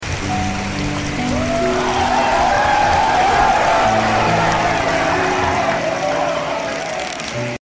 perlombaan tarik tambang ibu-ibu #17agustus